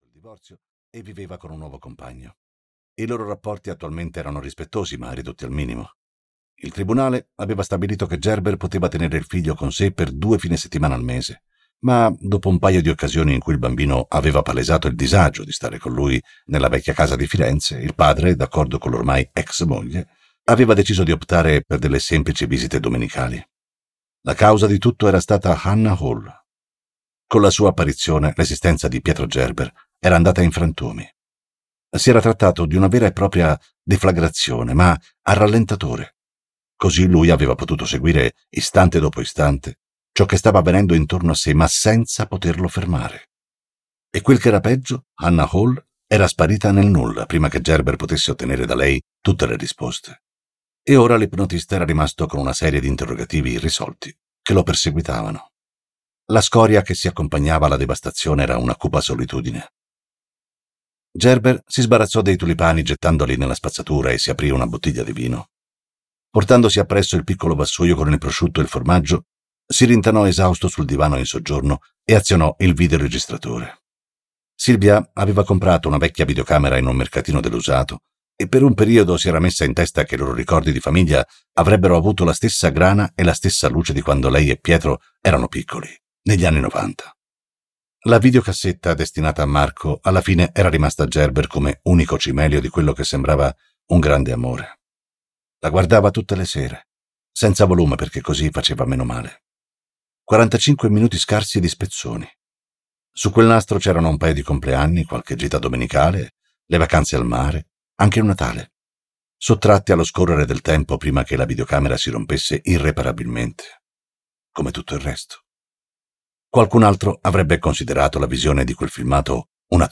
"La casa senza ricordi" di Donato Carrisi - Audiolibro digitale - AUDIOLIBRI LIQUIDI - Il Libraio